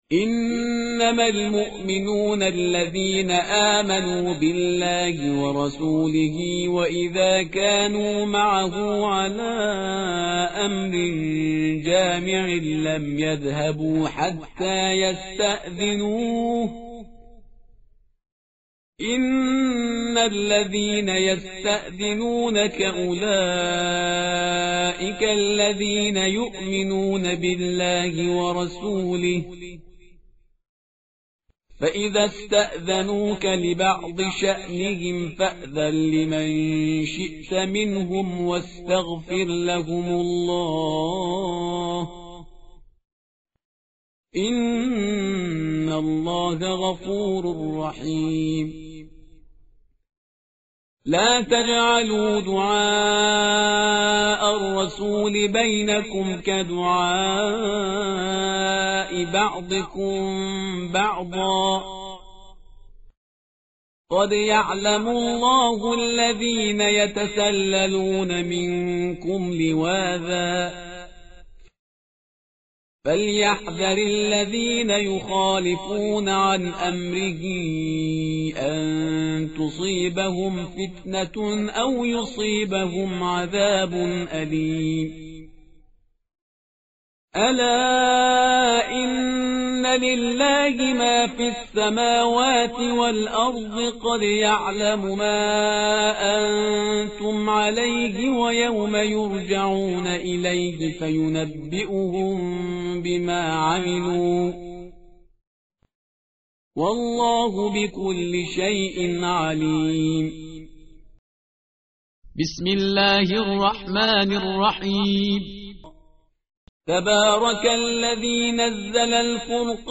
tartil_parhizgar_page_359.mp3